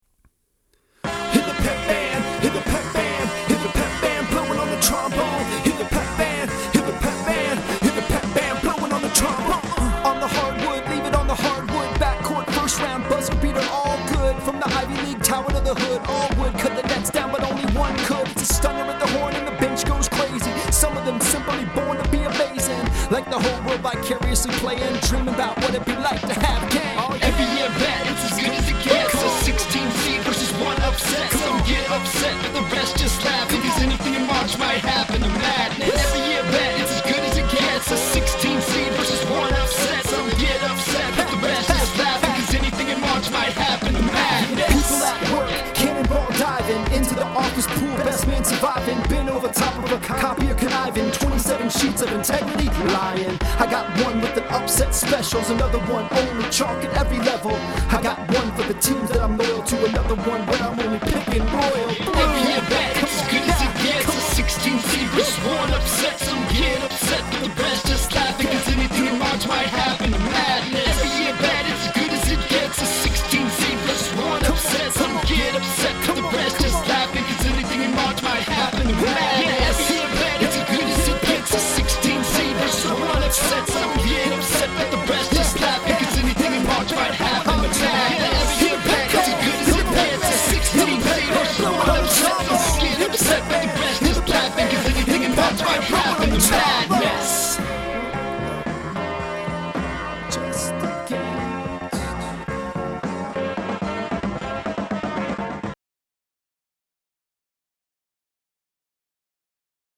I donated my lunch break to your rap news benefit.
A sonic frequency train wreck. Audio engineers, cover up.